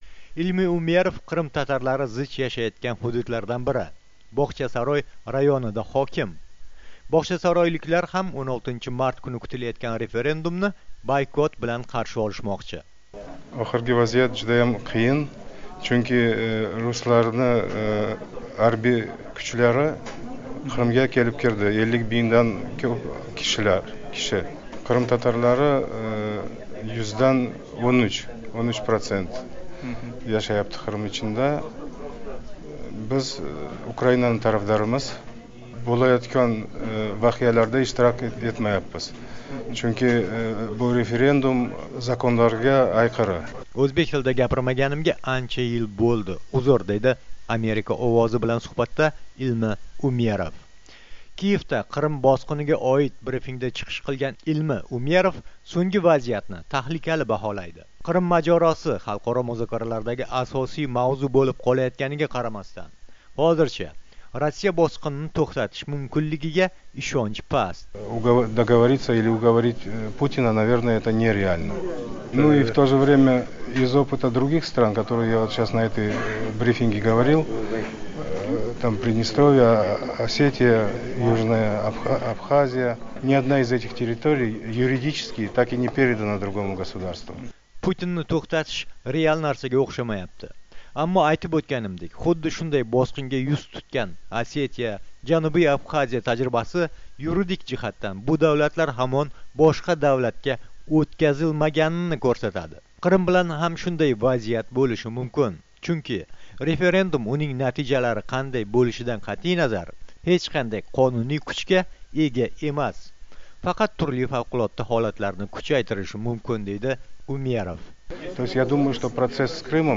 Qrimdagi Bog‘chasaroy hokimi Ilmi Umerov bilan suhbat